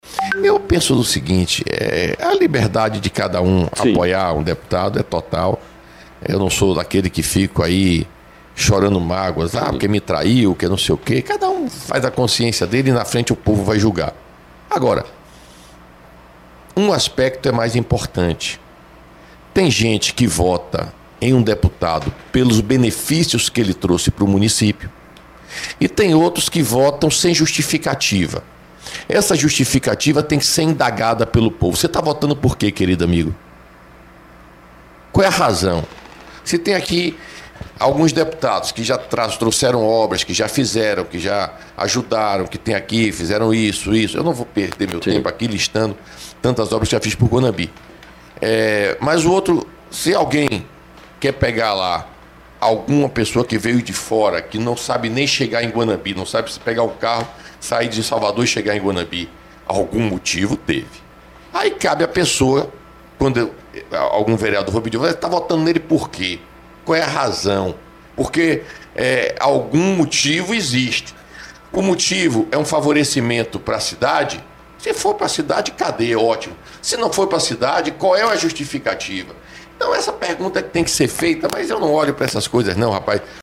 Durante a entrevista, Arthur Maia afirmou que cada pessoa tem liberdade para escolher em quem votar.